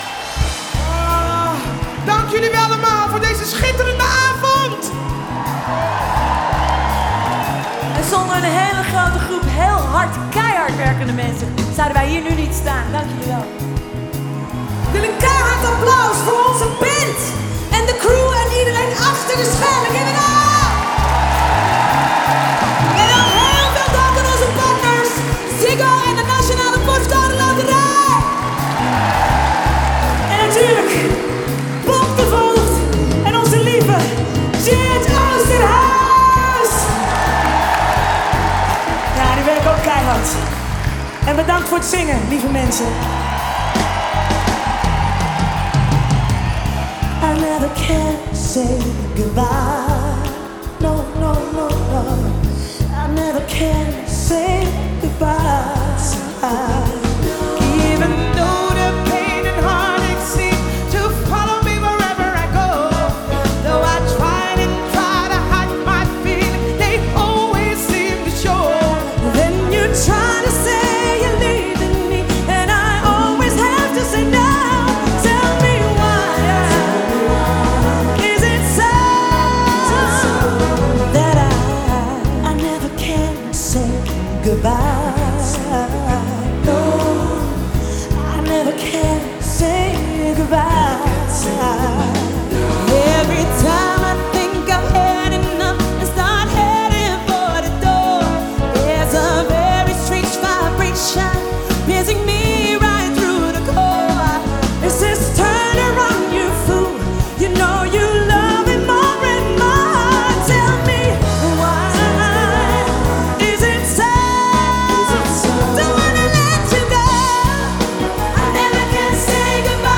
Genre: Soul.